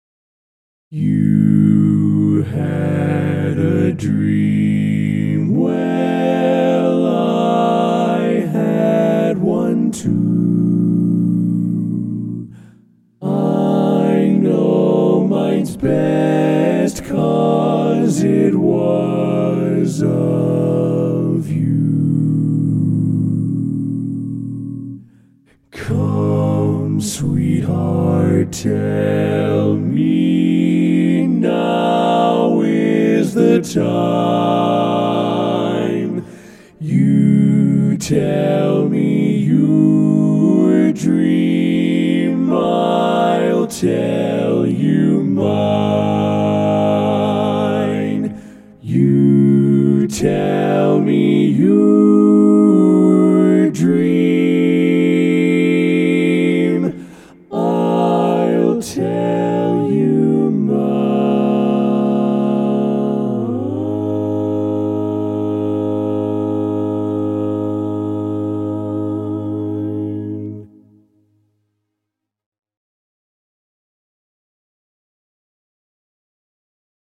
Barbershop
Full Mix